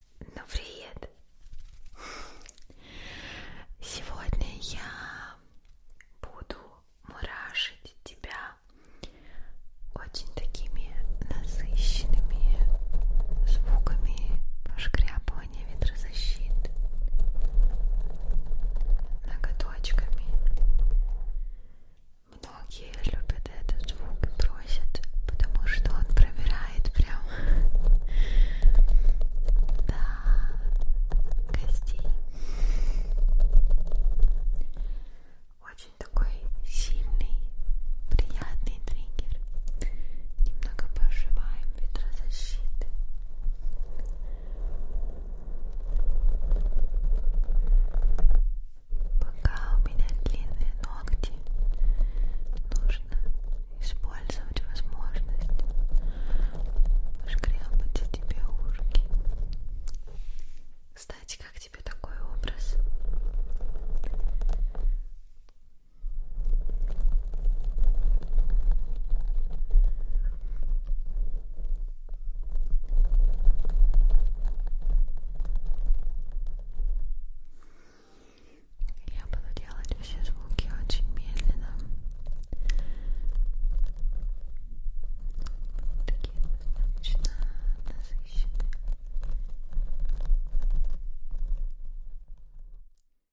Звук голоса юной девушки для ASMR